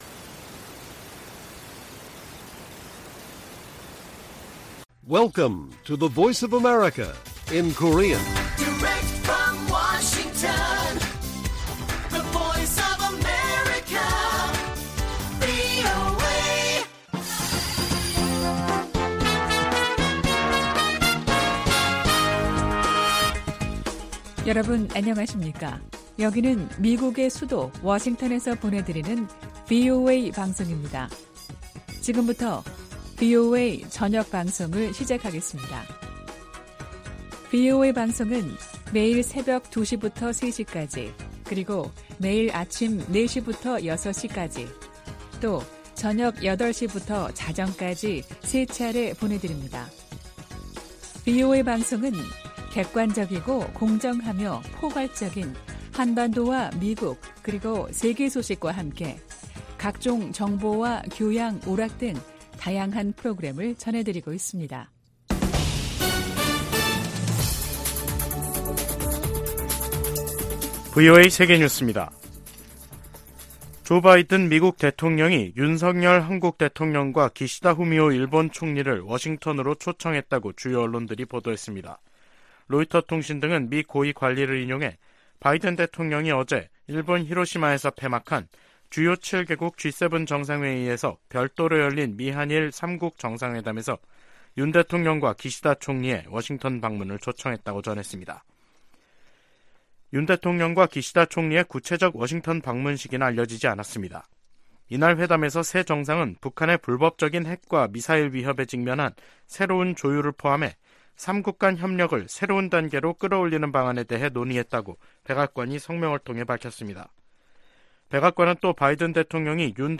VOA 한국어 간판 뉴스 프로그램 '뉴스 투데이', 2023년 5월 22일 1부 방송입니다. 조 바이든 미국 대통령과 윤석열 한국 대통령, 기시다 후미오 일본 총리가 히로시마 회담에서 새로운 공조에 합의했습니다. 주요7개국(G7) 정상들은 히로시마 공동성명에서 북한의 계속되는 핵과 탄도미사일 위협을 규탄했습니다. 미일 외교장관들이 북한의 완전한 비핵화를 위한 미한일 3각 공조의 중요성을 강조했습니다.